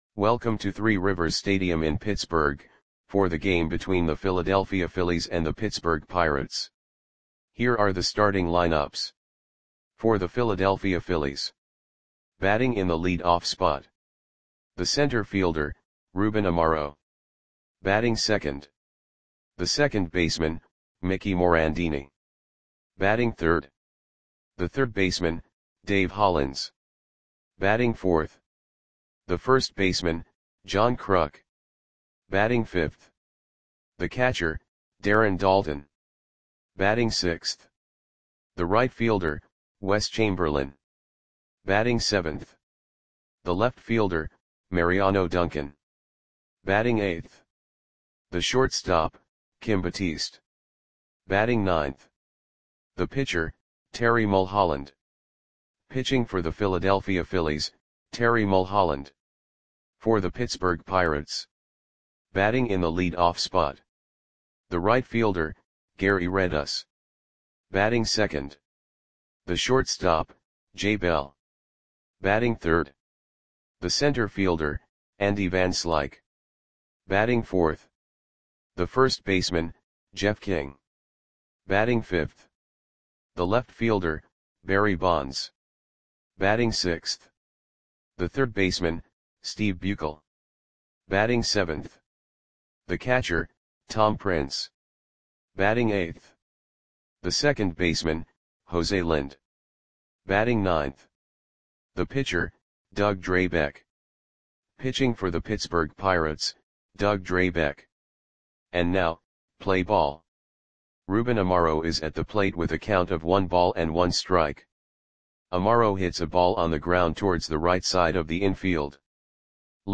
Lineups for the Pittsburgh Pirates versus Philadelphia Phillies baseball game on April 17, 1992 at Three Rivers Stadium (Pittsburgh, PA).
Click the button below to listen to the audio play-by-play.